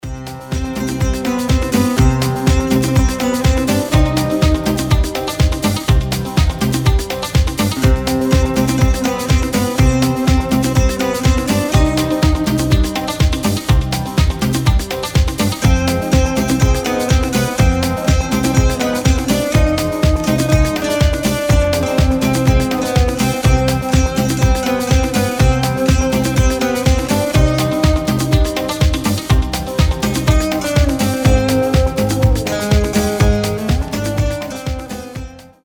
• Качество: 320, Stereo
гитара
заводные
без слов
восточные
Классный восточный мотив в рамках жанра нью-эйдж